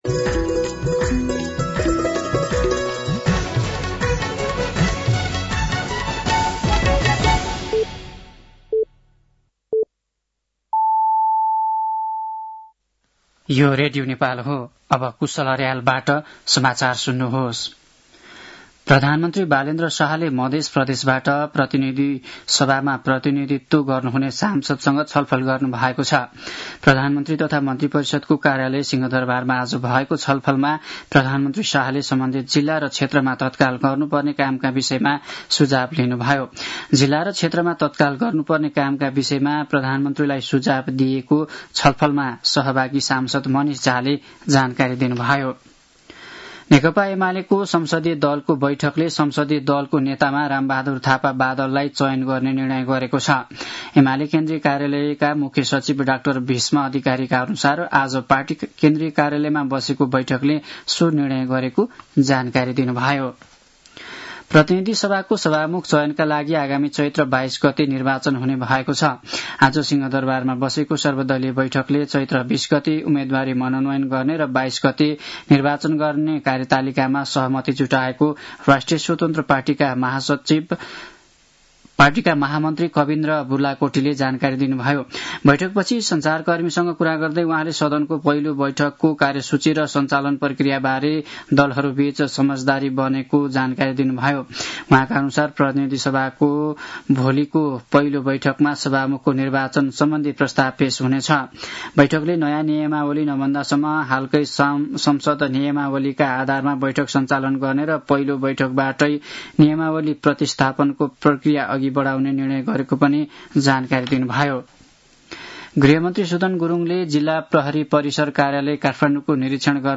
साँझ ५ बजेको नेपाली समाचार : १८ चैत , २०८२